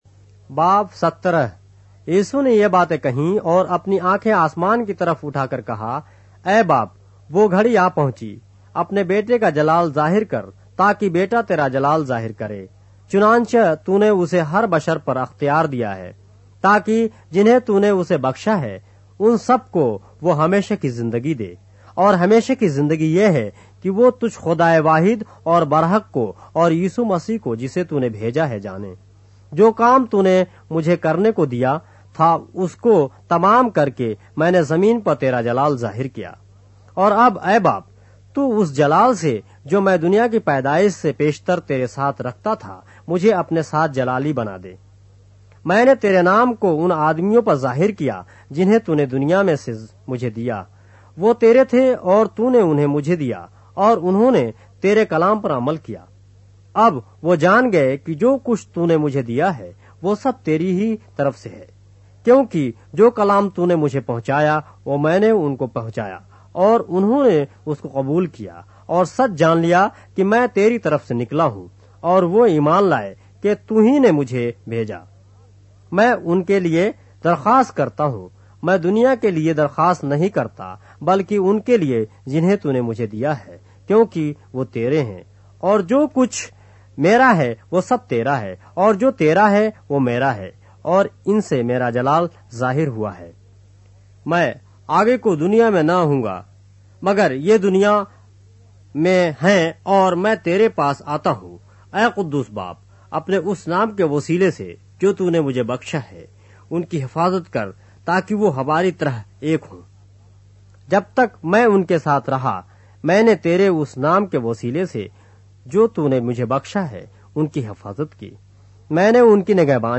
اردو بائبل کے باب - آڈیو روایت کے ساتھ - John, chapter 17 of the Holy Bible in Urdu